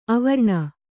♪ avarṇa